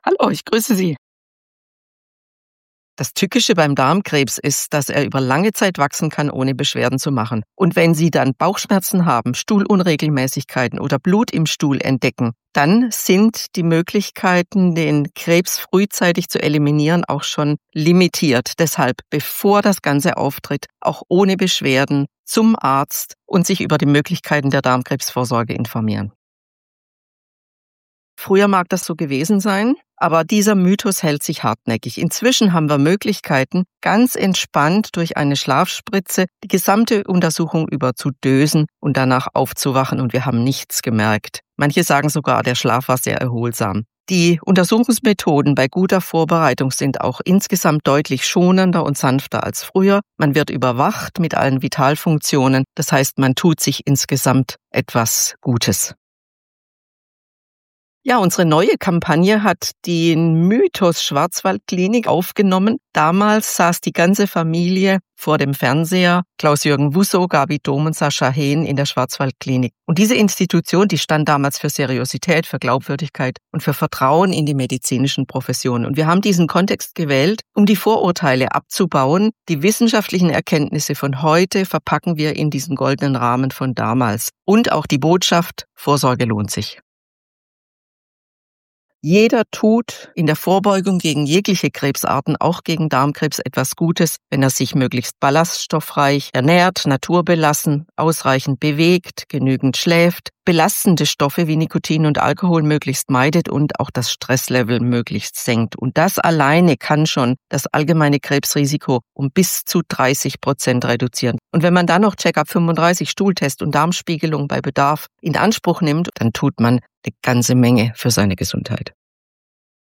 Interview
O-Ton-Paket_Darmkrebsmonat_Maerz_2026_radionews.mp3